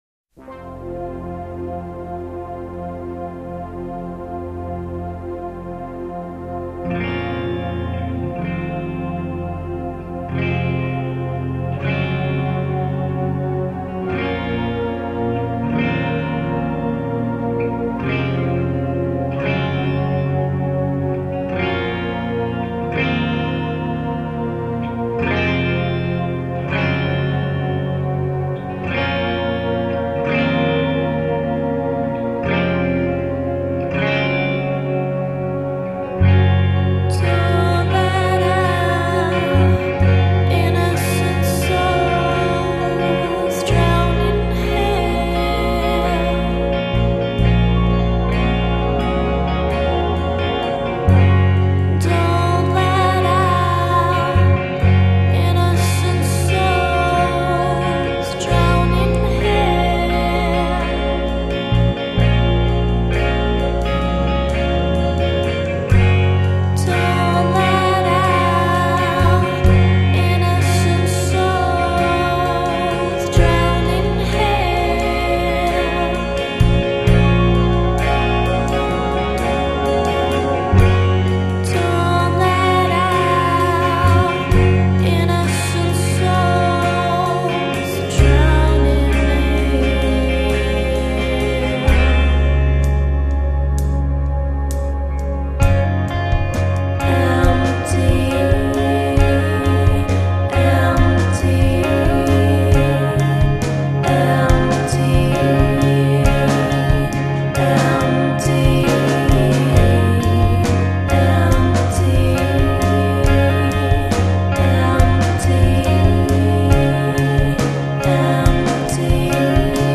One band I came across was an all-girl group from Wollongong